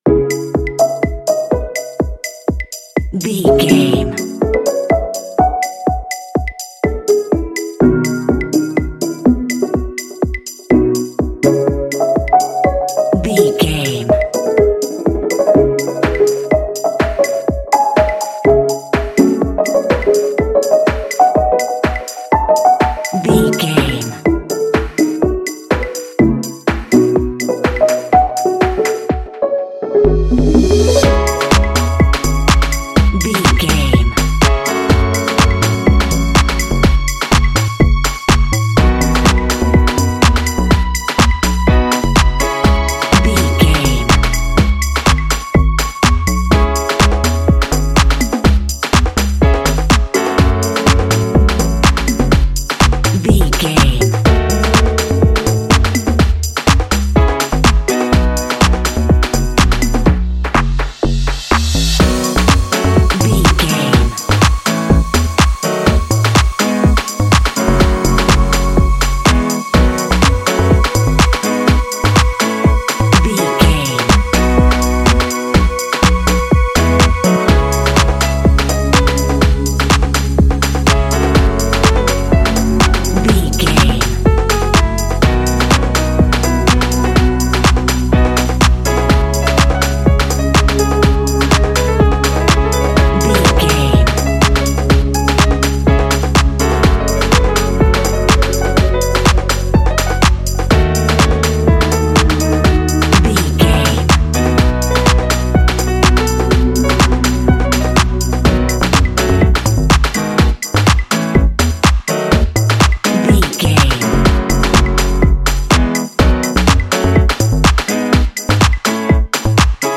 Blending afro house and deep house with warm synths
Its hypnotic groove invites both movement and reflection.
Ionian/Major
instrumental
soulful
minimal
electronic